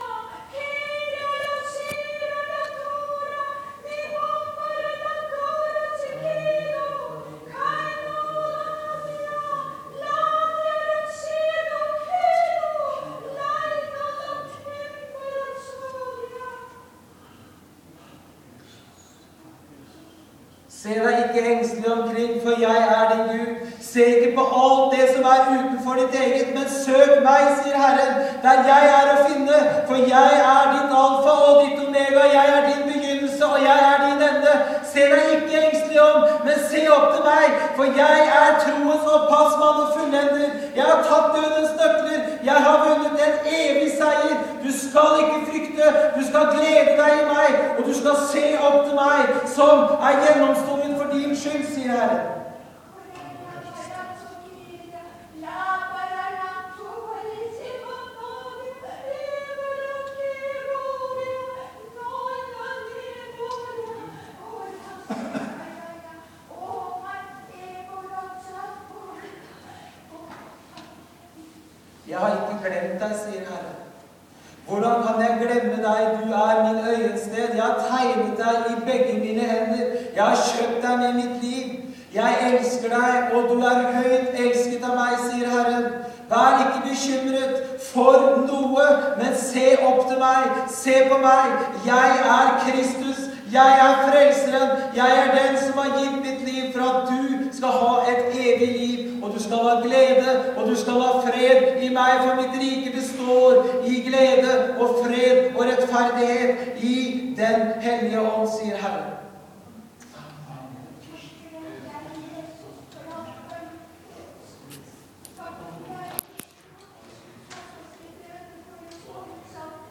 TYDING AV TUNGETALE.
Det var nattverdsmøte og vitne-møte, men allereie ved innleiinga av møtet, når vi skulle samle oss i bønn, kom ei kvinne med tungetale og ein ung mann tyda det: